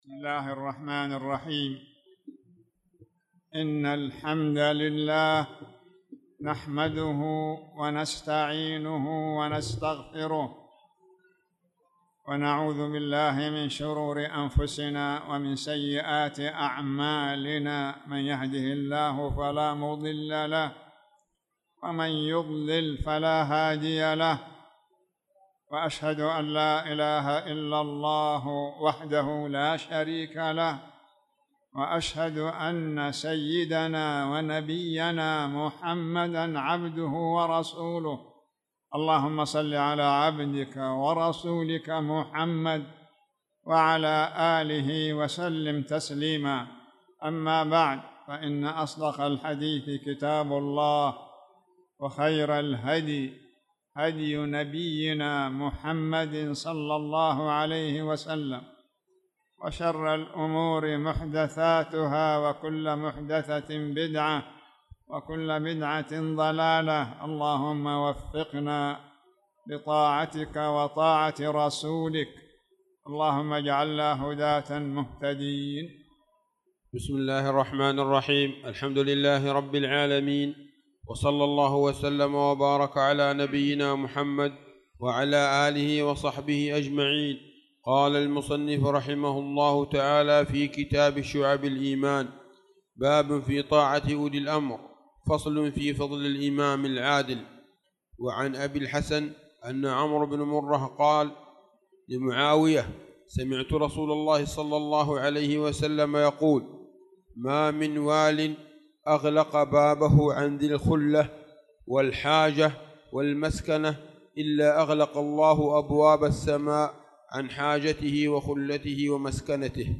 تاريخ النشر ٢٦ شوال ١٤٣٧ هـ المكان: المسجد الحرام الشيخ